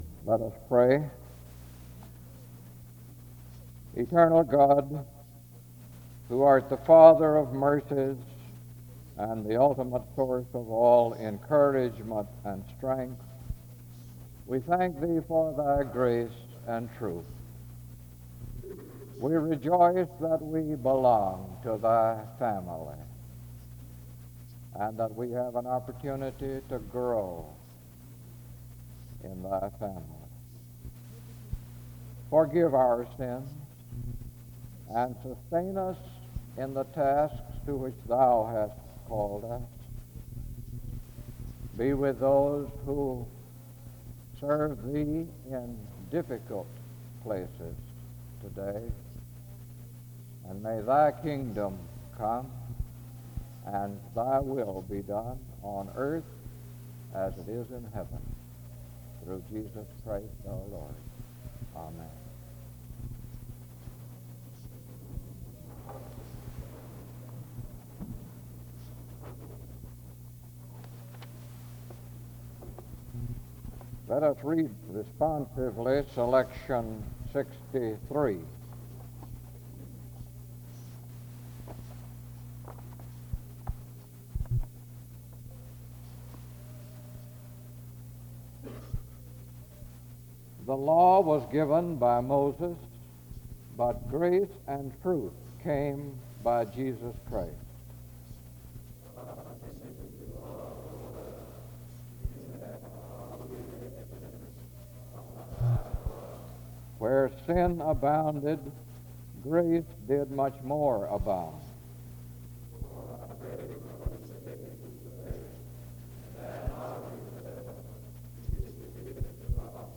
The service begins with a prayer (0:00-1:12). After which, the speaker reads a responsive reading (1:13-2:50). The service continues with a period of singing (2:51-5:19). A prayer is then offered (5:20-5:39).